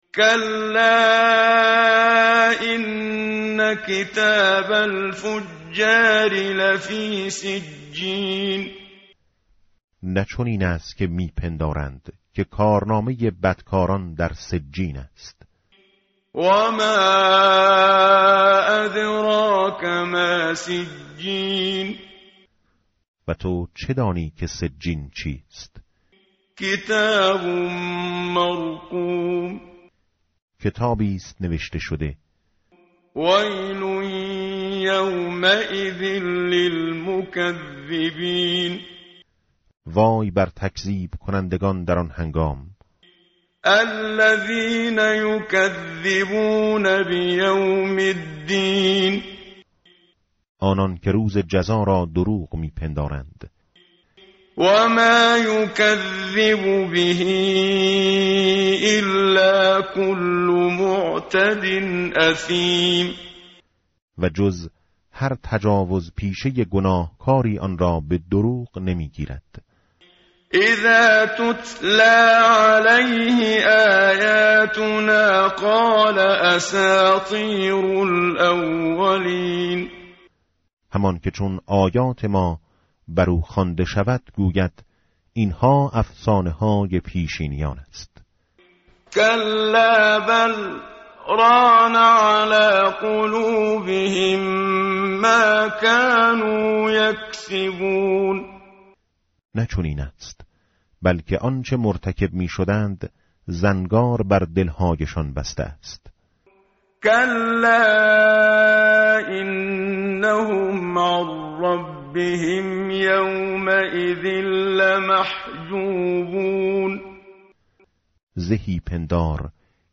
tartil_menshavi va tarjome_Page_588.mp3